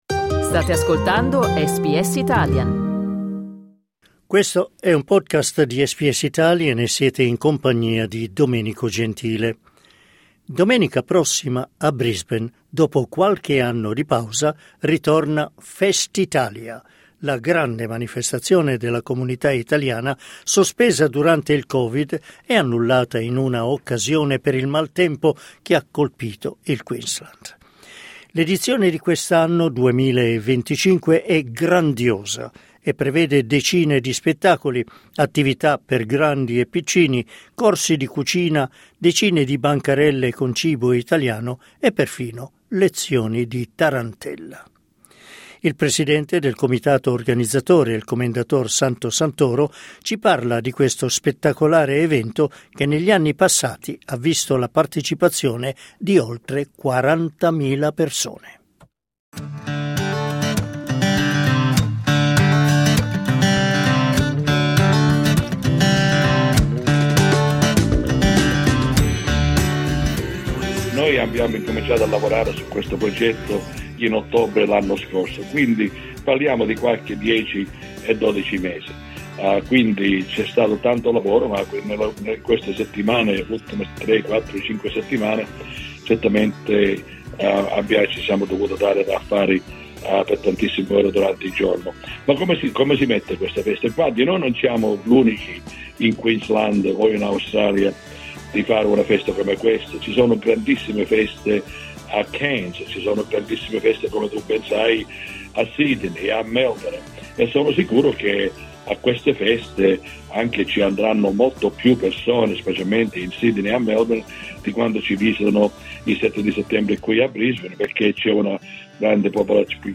Clicca sul tasto "play" in alto per ascoltare il servizio Il cibo sarà uno degli aspetti importanti di Festitalia.